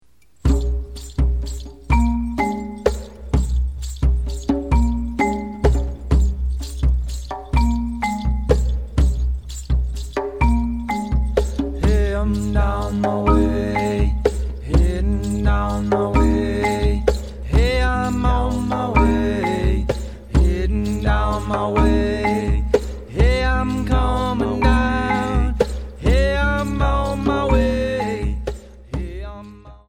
Compositions for Dance and Movement